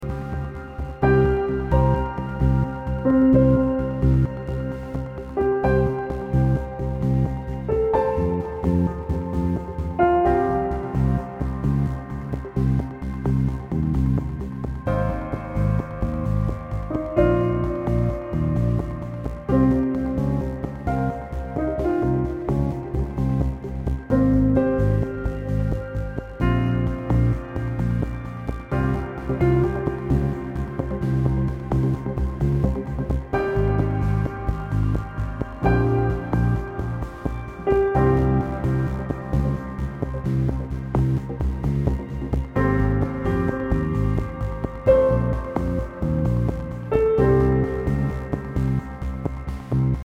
Experimental >
Electronica >
Post Classical >